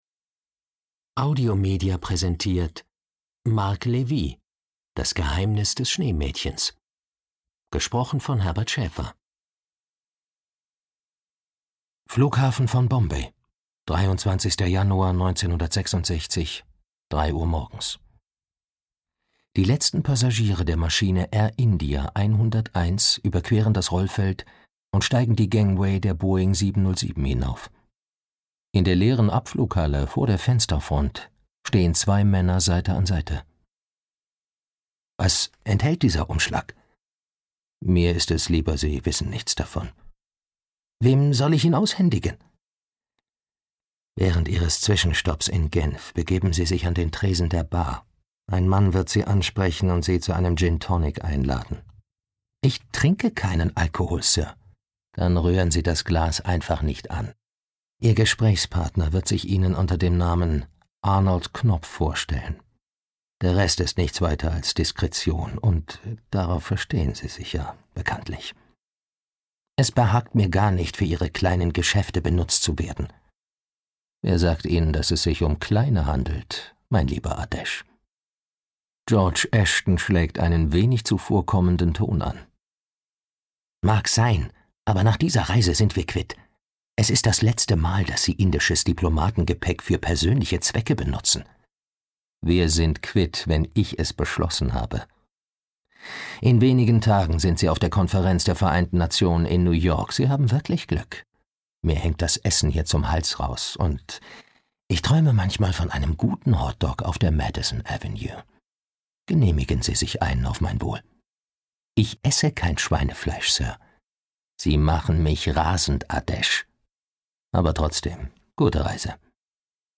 Das Geheimnis des Schneemädchens - Marc Levy - Hörbuch